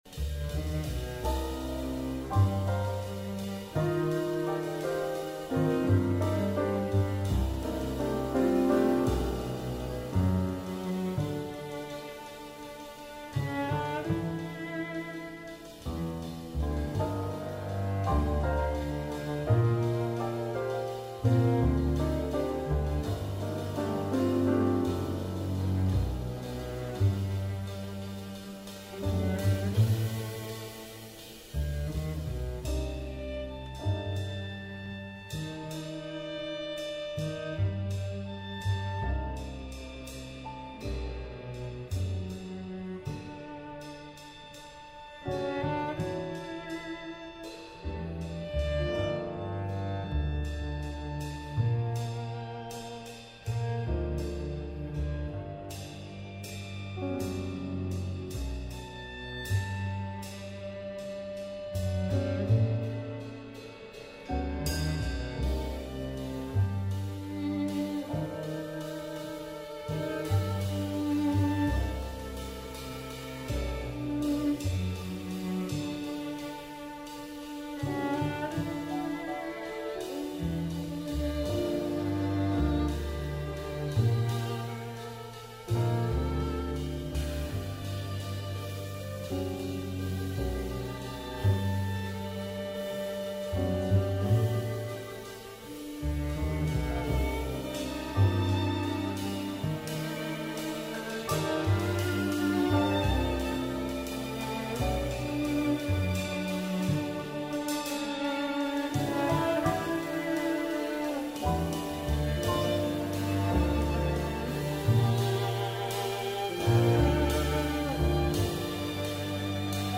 Violins
Viola
Cello
Piano
Drums
Contrabass